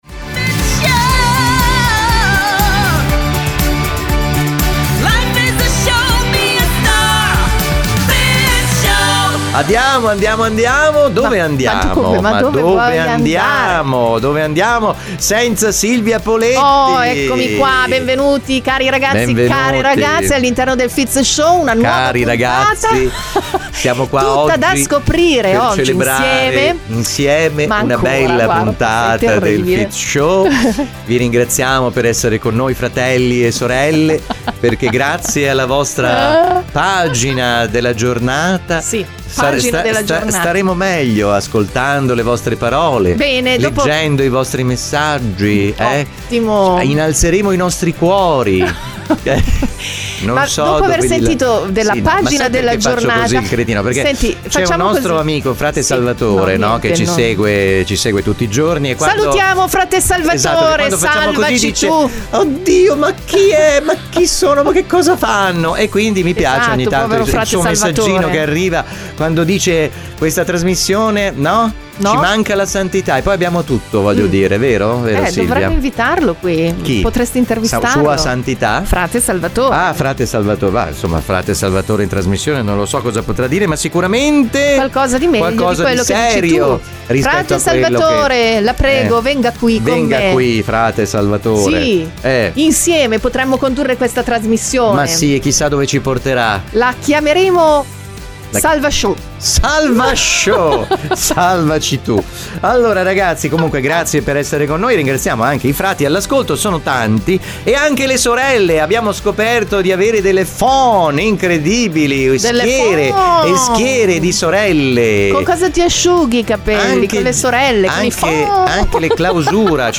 - Fizz Show, la trasmissione tra le più ascoltate in Italia. Conduzione a due voci.
Le nostre produzioni hanno sigle di livello professionale e voci fuori campo nazionali e internazionali.